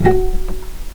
vc_pz-F#4-pp.AIF